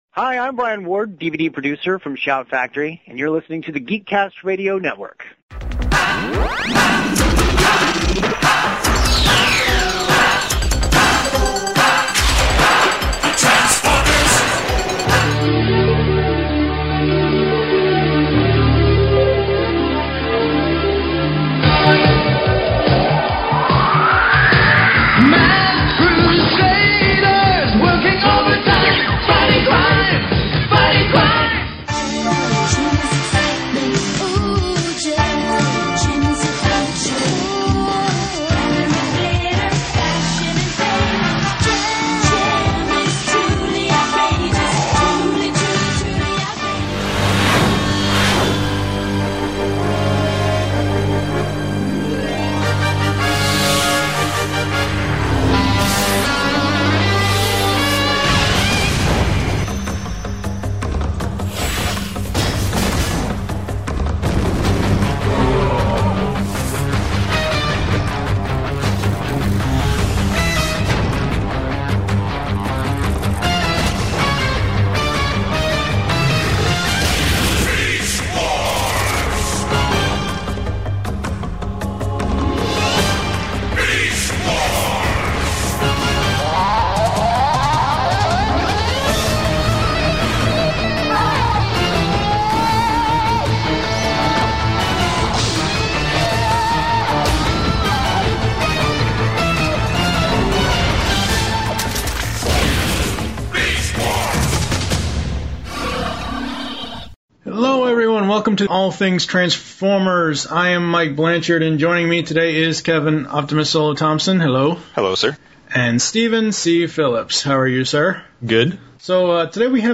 This time we bring you a new type of interview.